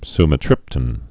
(smə-trĭptn)